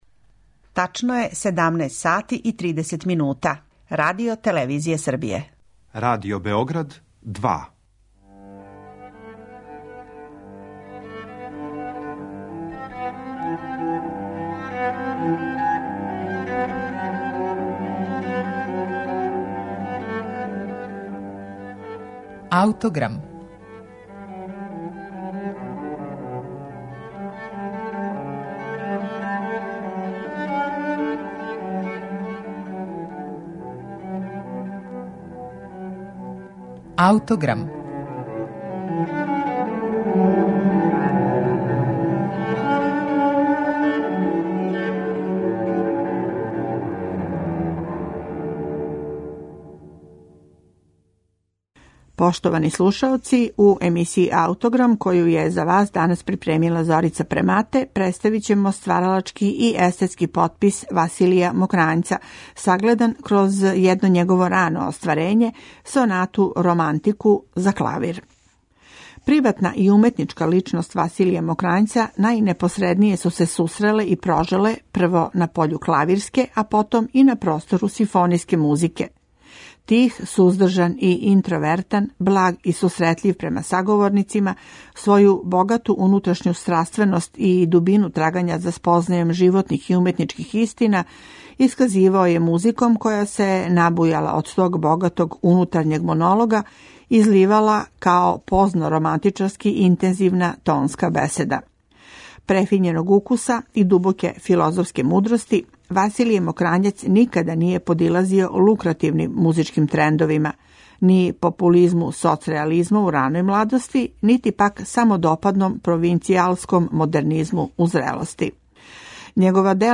Овога пута слушаћете једно рано остварење нашег великог композитора ‒ академика Василија Мокрањца. „Sonata romantica" за клавир настала је док је још био на студијама, 1947. године, као један од његових првих опуса.